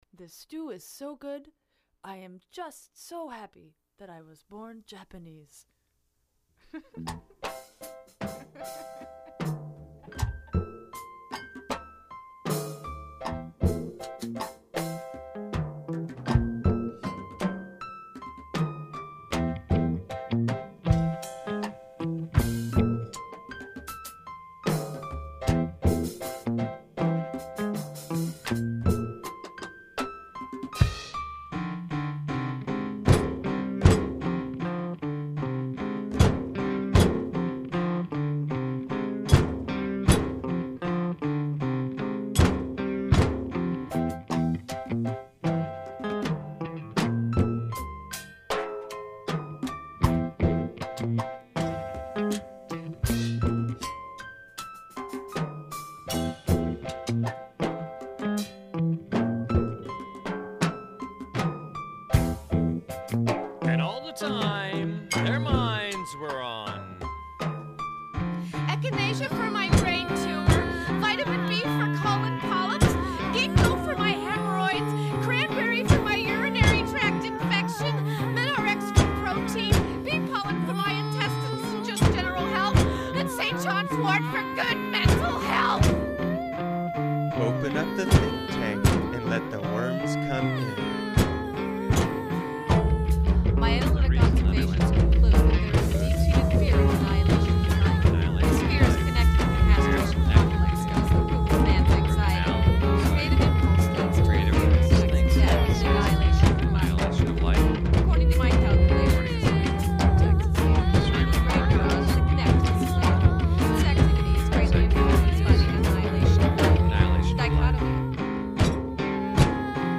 soap-box operatic